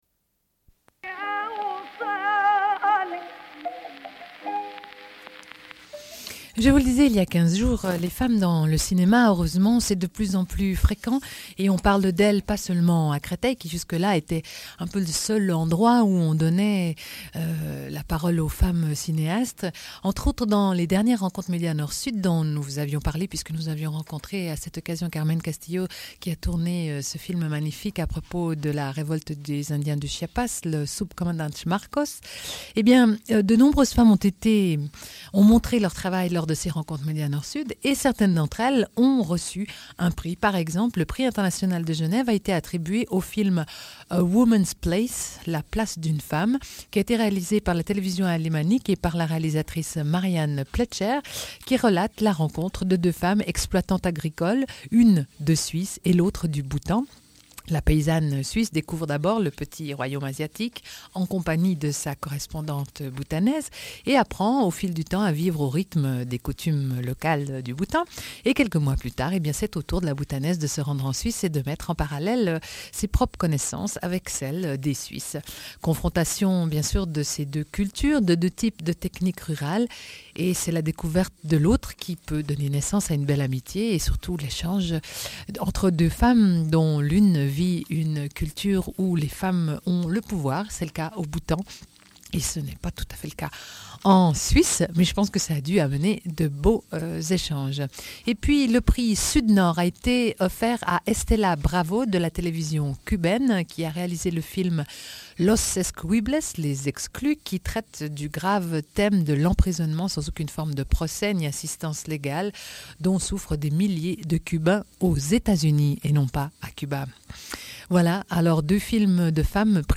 Suite de l'émission : lecture d'un texte de Susan J. Elliott (Nouvelle-Zélande), intitulé « Dignité pour tous ».